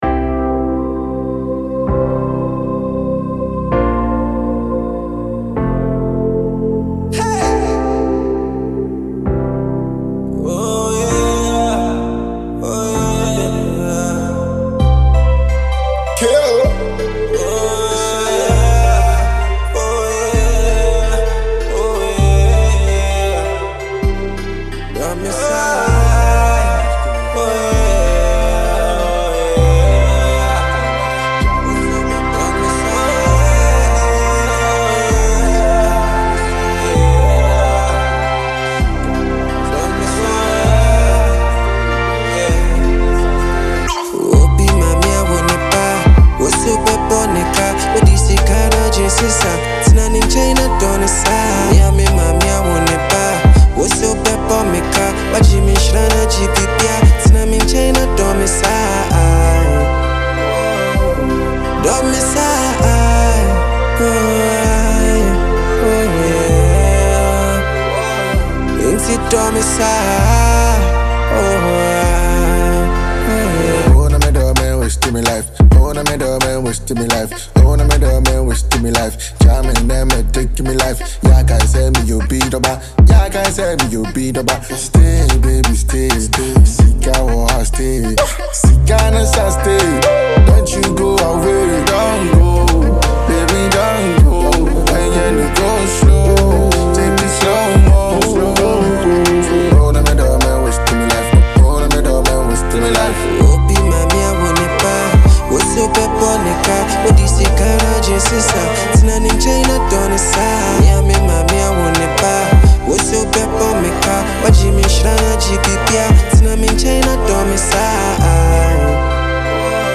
Ghanaian trapper